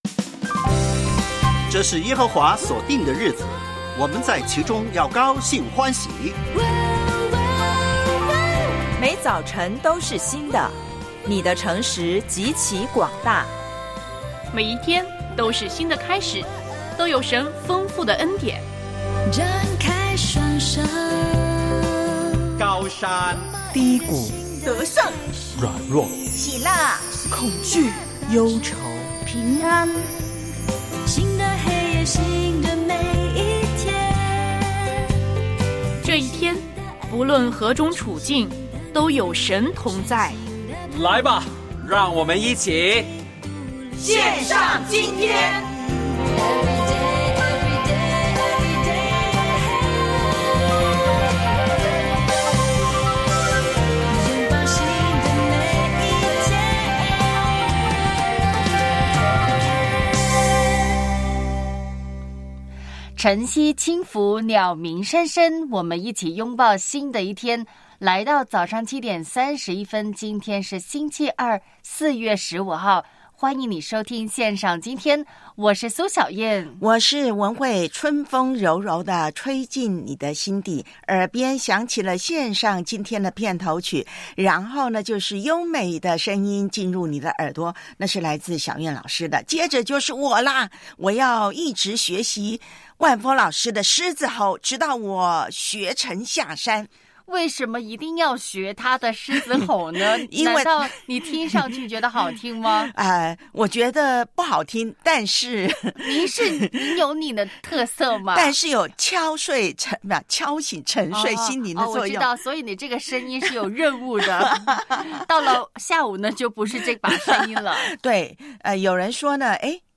教会年历灵修：约翰福音12:20-26 ；复活节广播剧（2）耶稣被钉；我爱背金句：撒母耳记上7:12